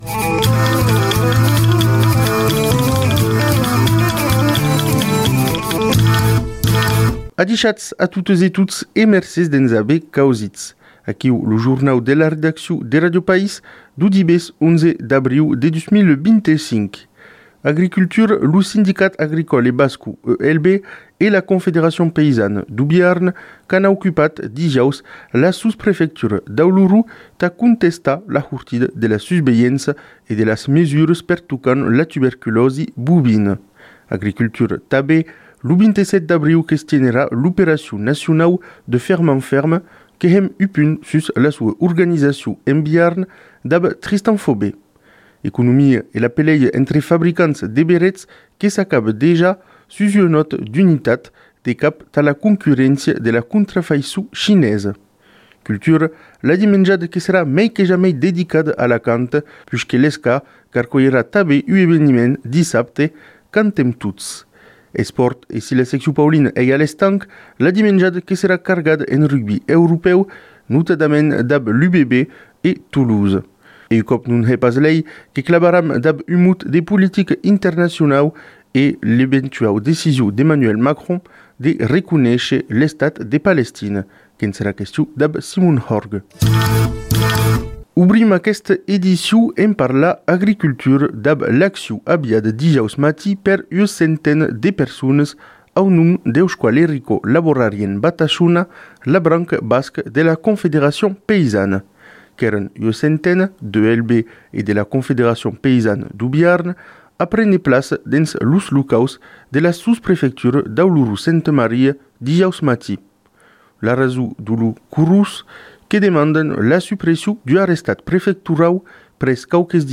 Revista radiofonica realizada en partenariat amb Ràdio Occitania, Ràdio País, Ràdio Albigés, Ràdio Nissa Pantai e Ràdio Cultura del Brasil.